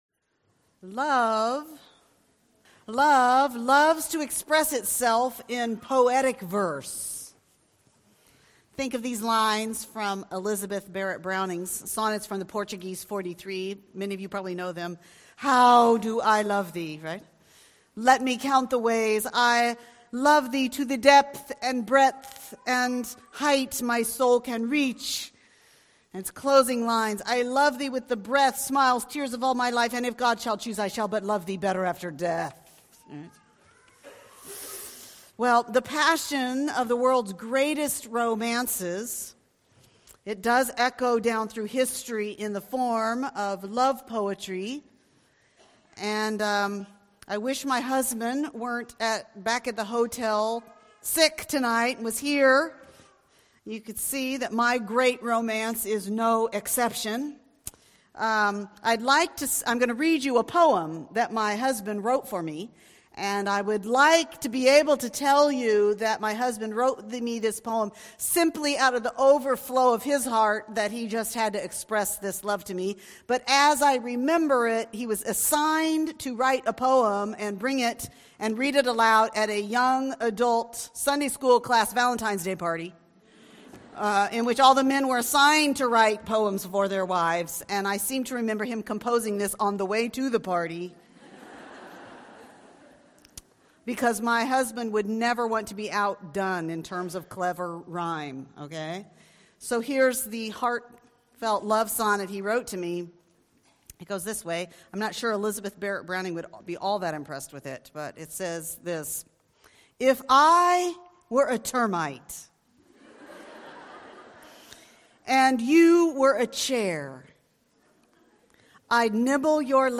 catch up on the talks from our BW Spring Conference 2019 'Even Better Than Eden'